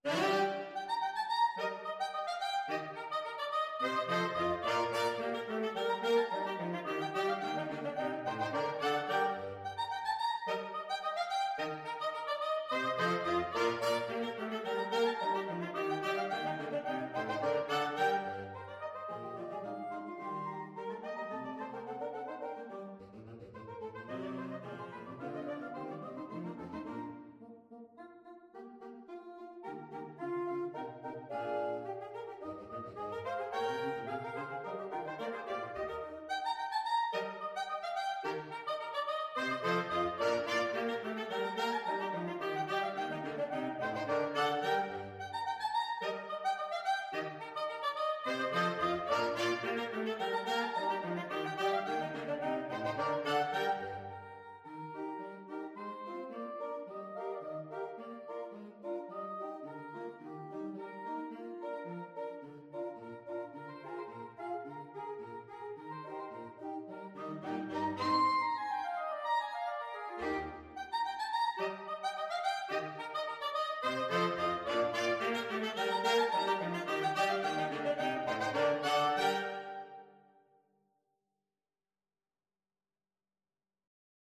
Free Sheet music for Saxophone Quartet
Soprano SaxophoneAlto SaxophoneTenor SaxophoneBaritone Saxophone
G minor (Sounding Pitch) (View more G minor Music for Saxophone Quartet )
2/2 (View more 2/2 Music)
Allegro ridicolo = c. 108 (View more music marked Allegro)
Saxophone Quartet  (View more Intermediate Saxophone Quartet Music)
Classical (View more Classical Saxophone Quartet Music)